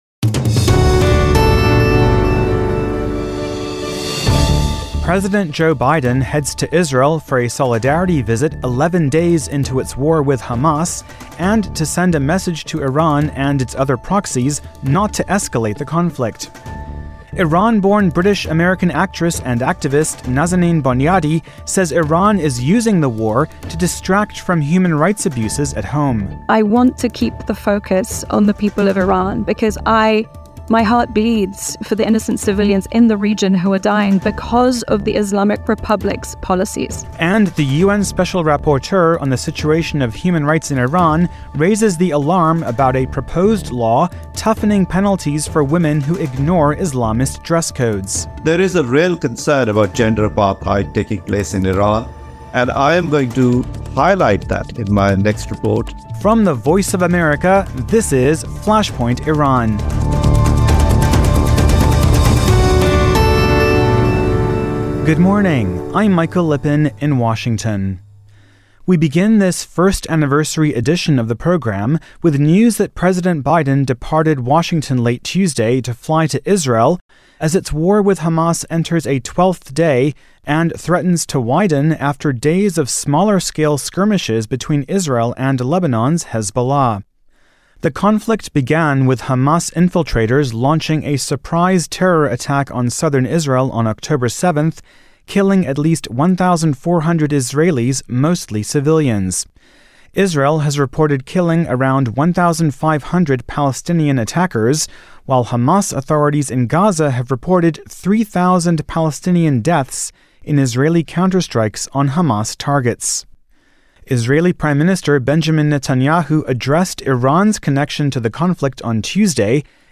Leaders of Israel, Iran and the U.S. on Tehran’s connection to Israel-Hamas war. Iran-born British American actress and activist Nazanin Boniadi and U.N. Special Rapporteur on situation of human rights in Iran Javaid Rehman speak to VOA at the National Union for Democracy in Iran conference.